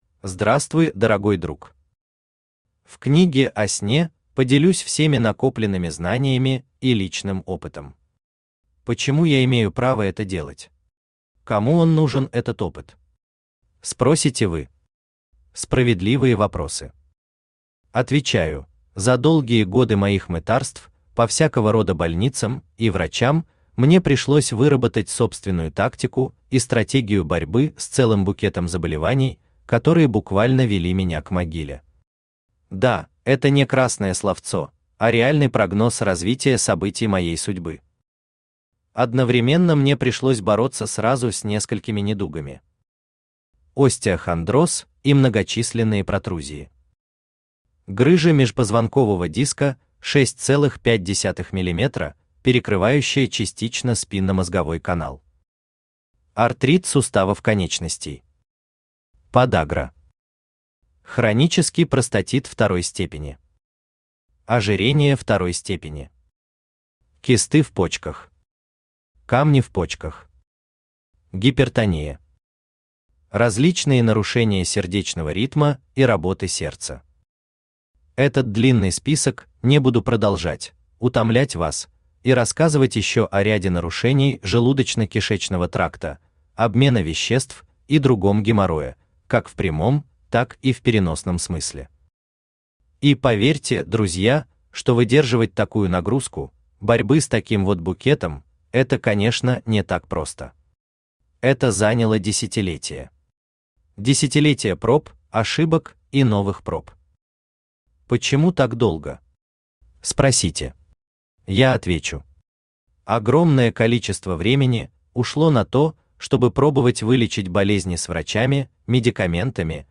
Аудиокнига Ваш сон станет крепким | Библиотека аудиокниг